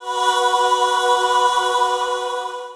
Key-choir-179.1.1.wav